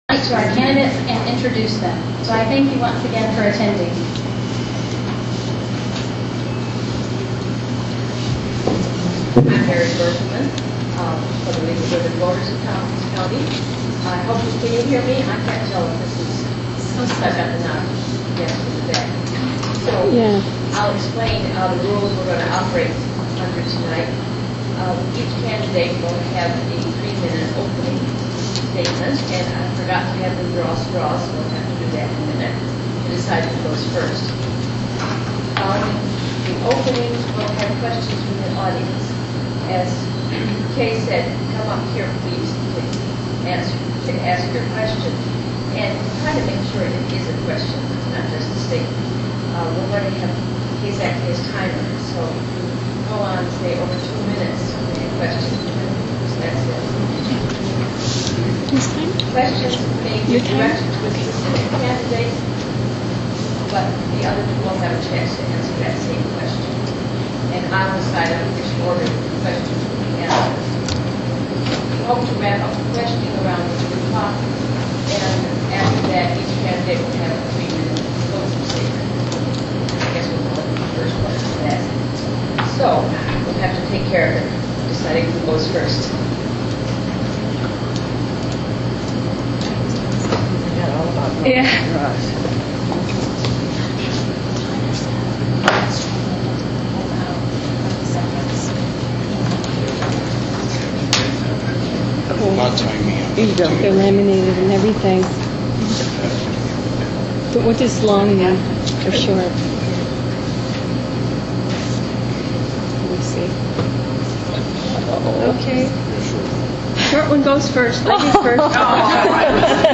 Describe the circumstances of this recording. Each candidate had a set amount of time to answer questions, and three minutes for opening and closing statements. The candidates drew straws to set the order of opening statements.